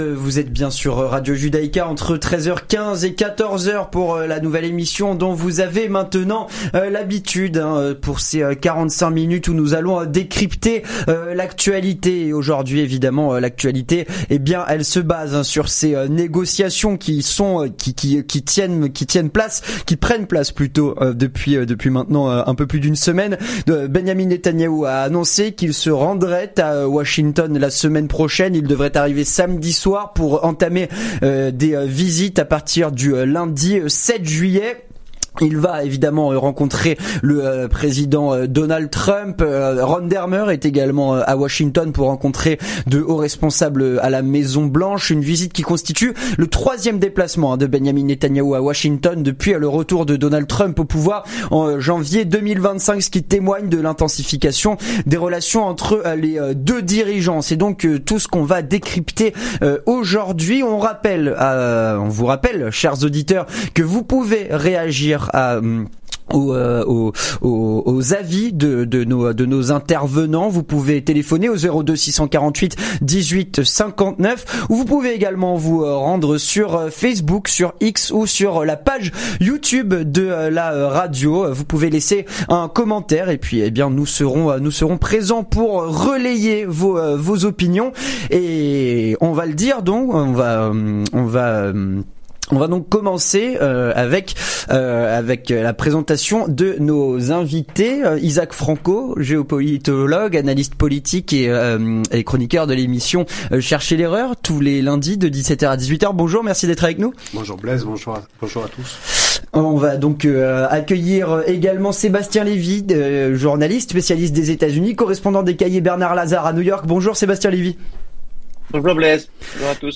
Le Grand Débat - Les enjeux de la visite de Benjamin Netanyahou à Washington.